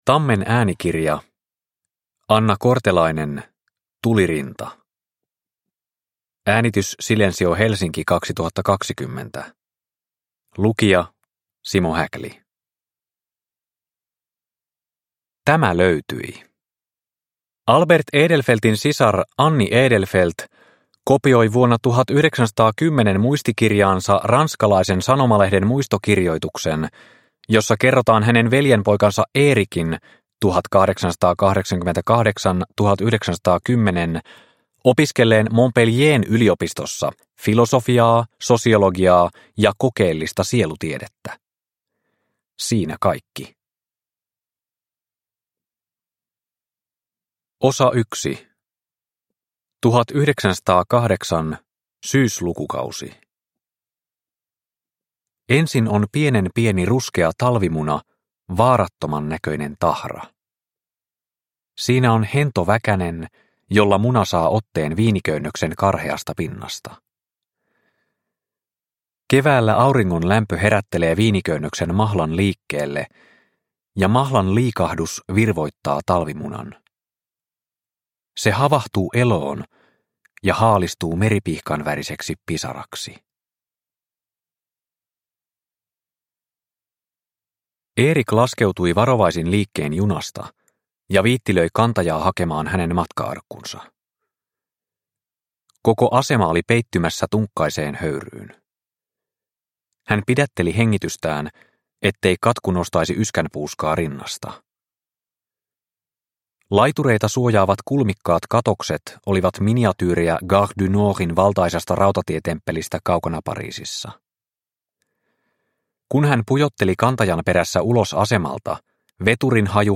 Tulirinta – Ljudbok – Laddas ner